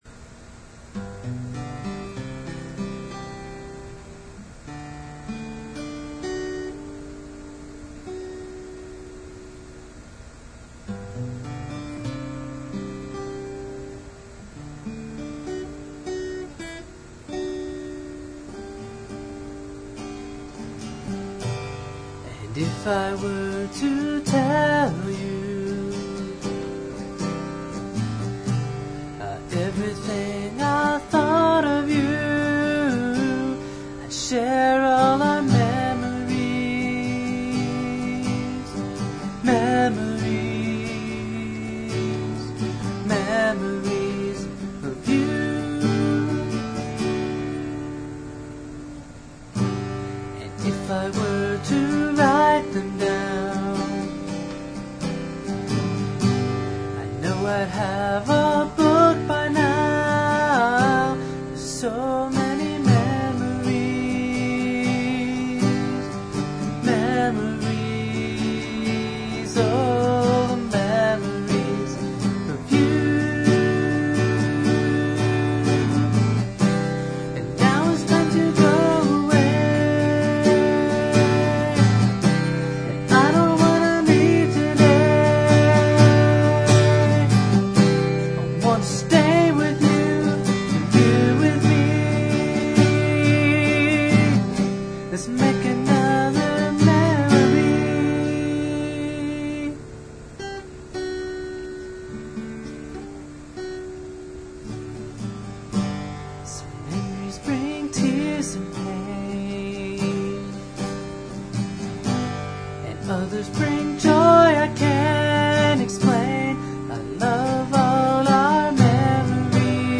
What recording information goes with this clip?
The songs on the site are rough cuts.